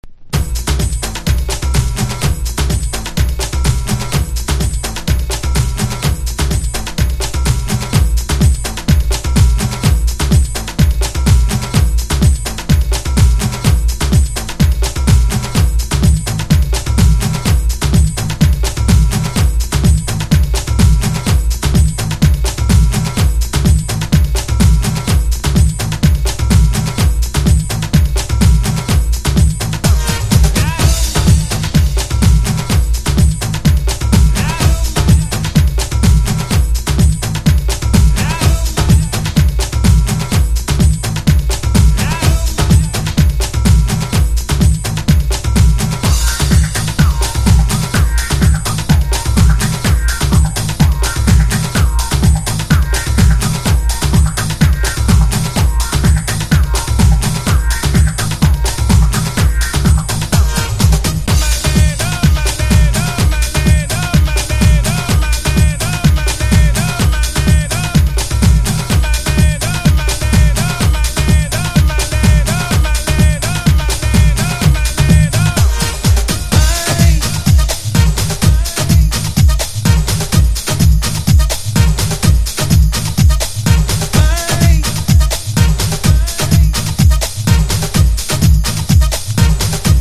CLUB MIX